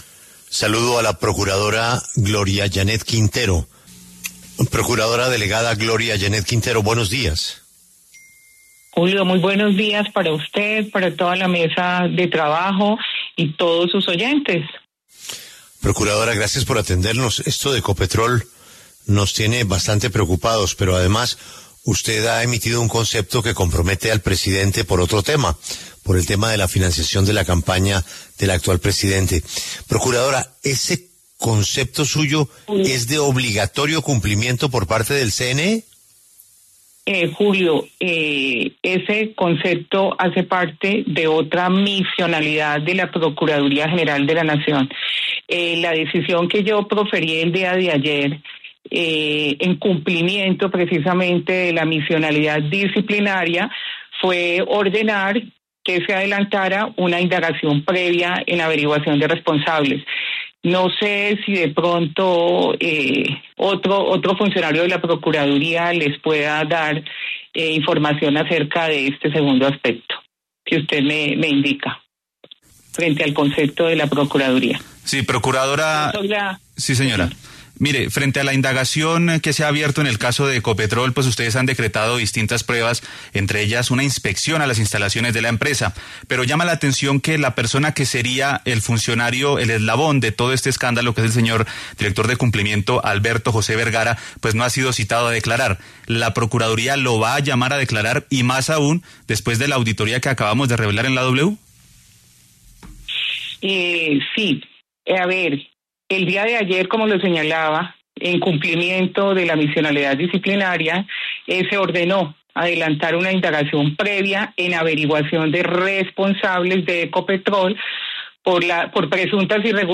En diálogo con La W, la procuradora que tomó la decisión de abrir indagación contra la estatal se refirió a las acciones que adelantarán para esclarecer el otrosí y las interceptaciones.